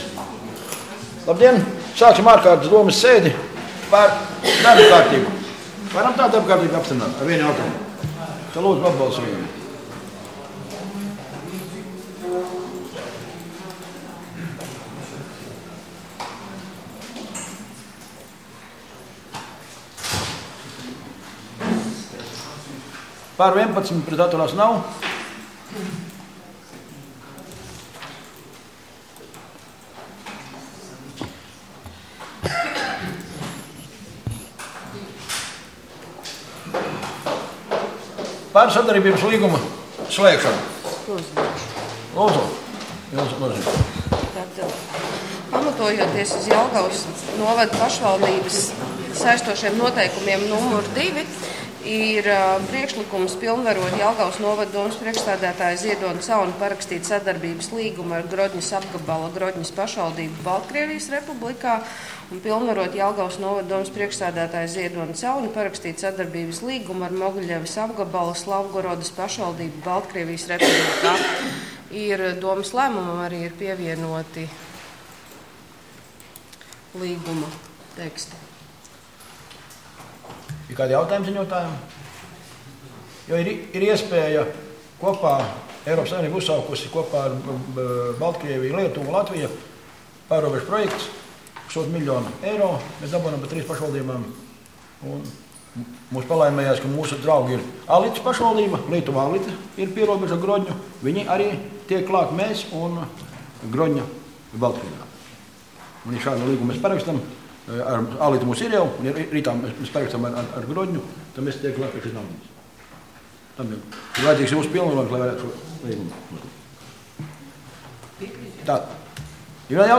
Domes ārkārtas sēde Nr. 13